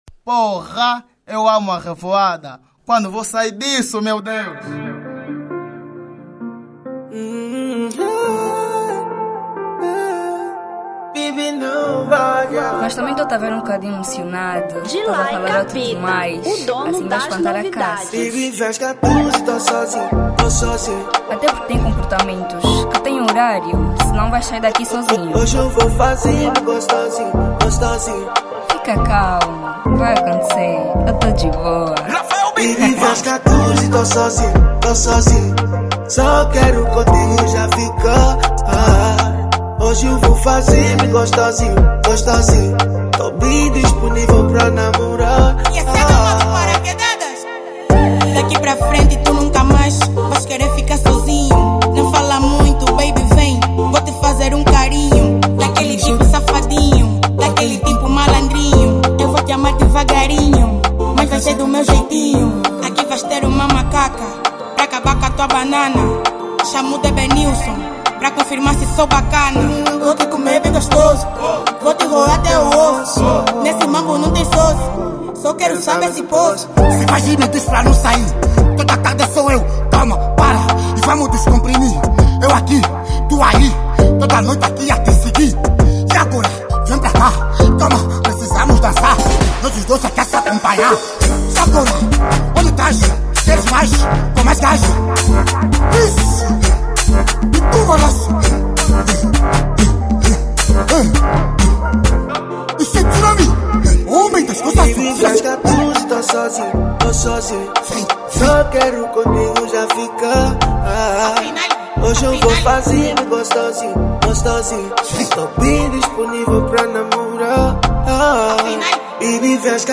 Funk 2025